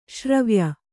♪ śravya